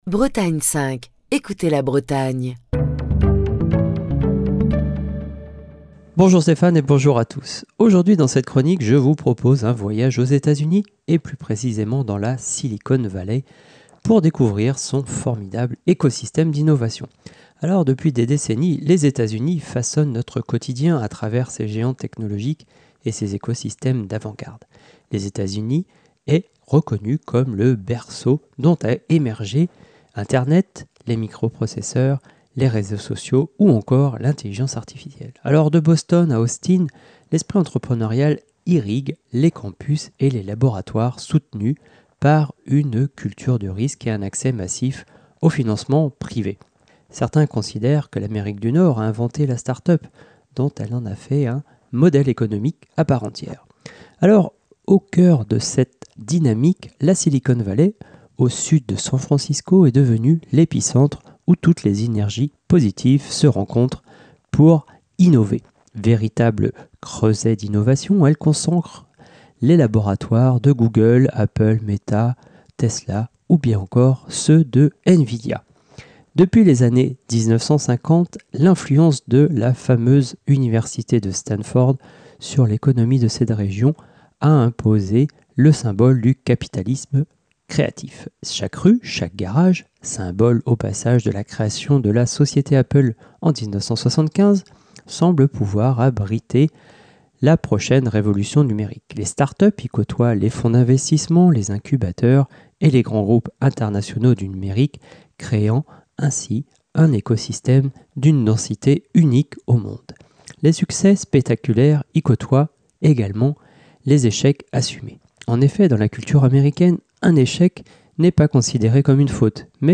Chronique du 3 novembre 2025.